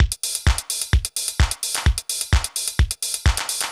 TEC Beat - Mix 2.wav